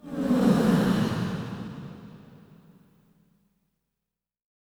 SIGHS 2   -L.wav